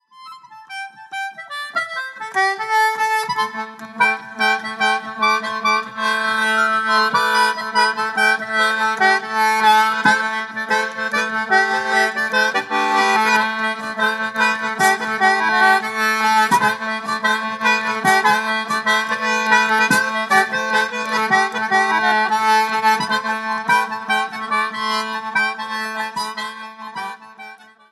adds more bass notes than is usual for Irish players